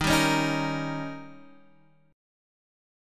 D#7 Chord
Listen to D#7 strummed